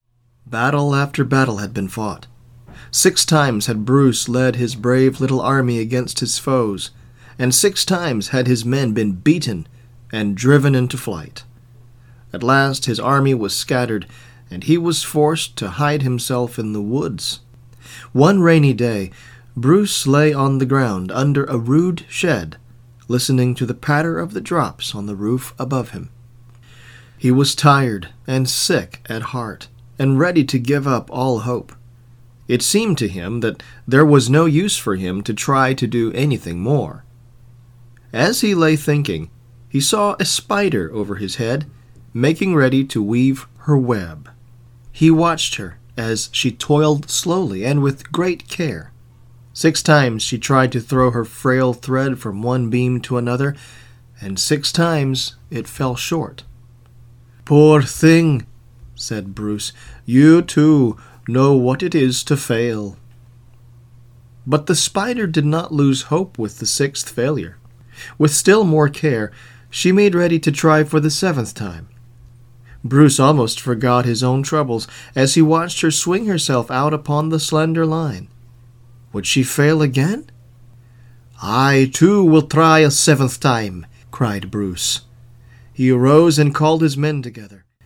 with varying accents for the different characters